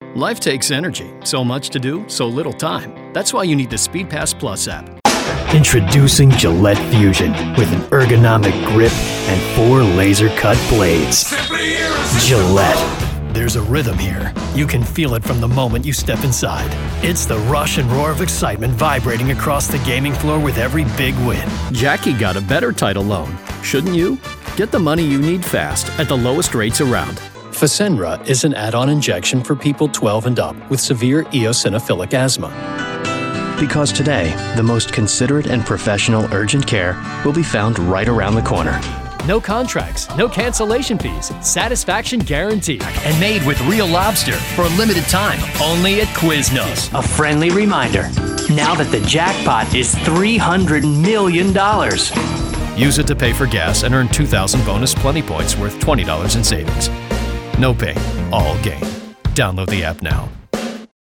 new york : voiceover : commercial : men